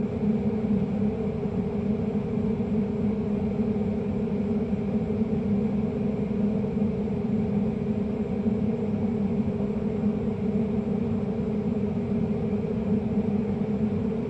17 机器标记的转弯
描述：机器标记转弯